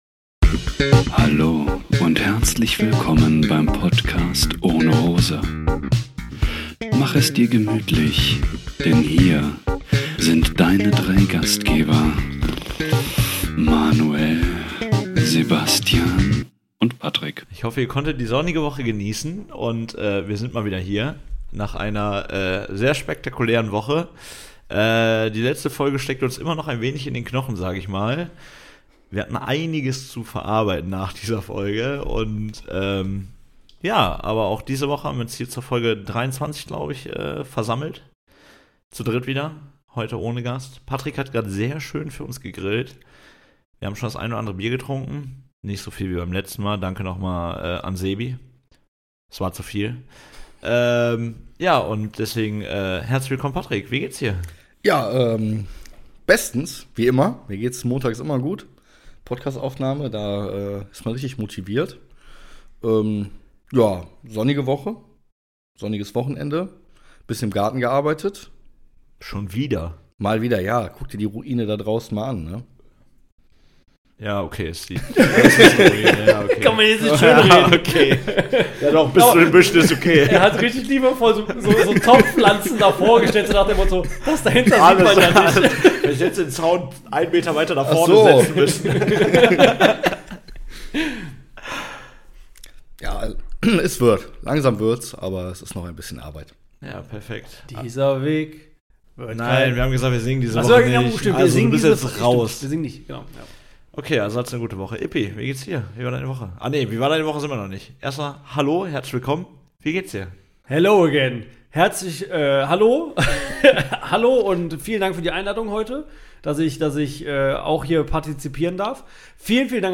In heiterer Runde verbringen wir diese Folge mal wieder nur zu dritt und bereiten euch hoffentlich etwas Vorfreude aufs Wochenende :)